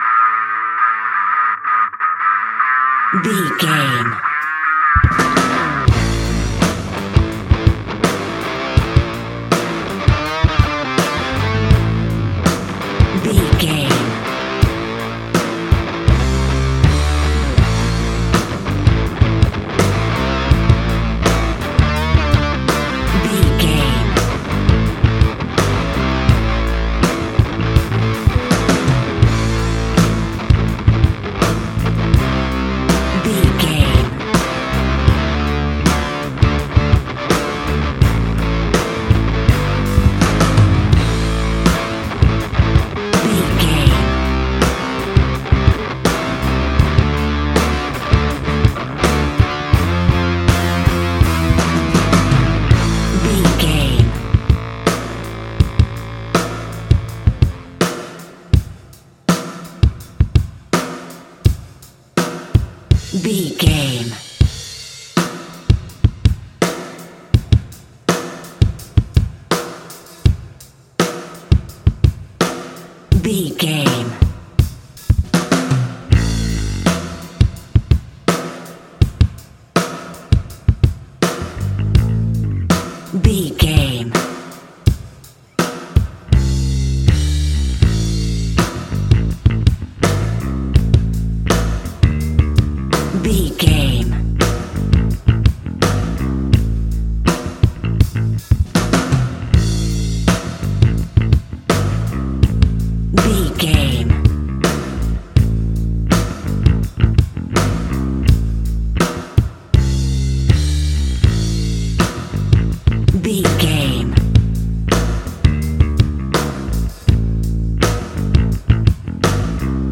Ionian/Major
A♯
hard rock
heavy rock
distortion